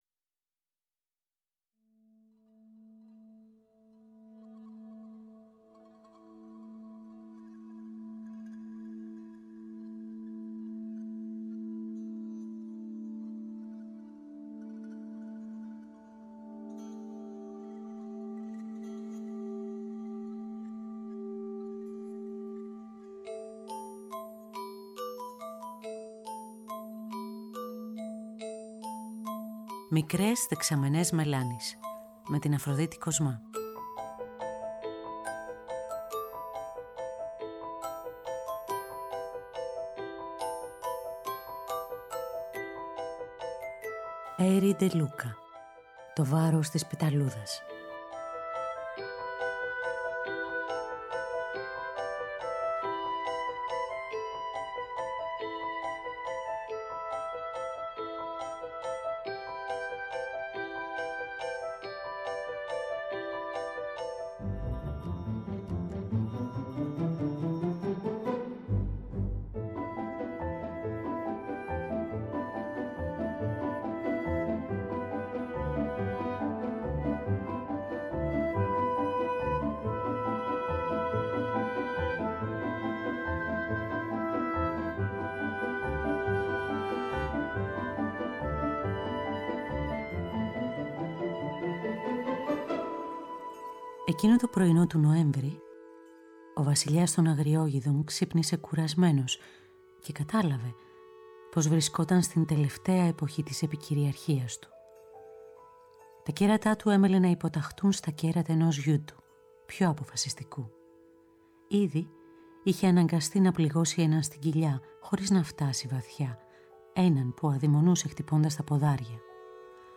αφήγηση